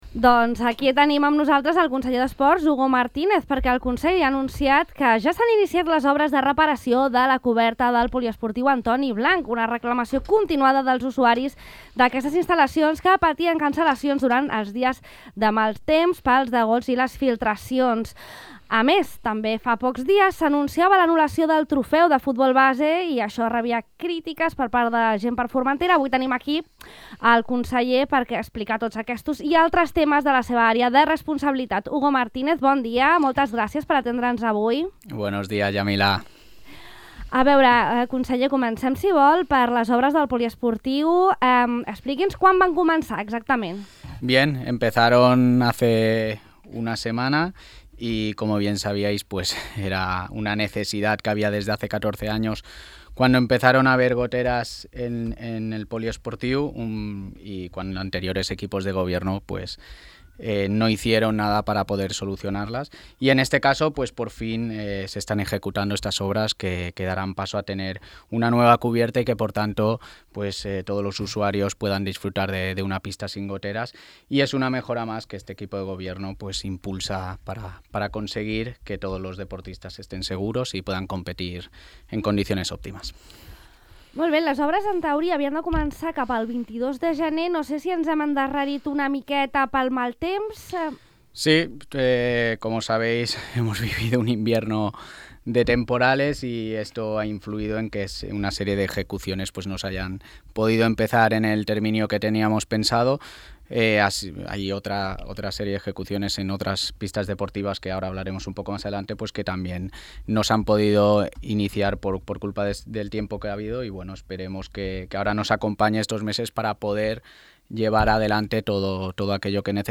El vicepresident tercer i conseller d’Esports i Recursos Humans ha explicat a Ràdio Illa els motius de la cancel·lació del Trofeu de Futbol Base de Formentera.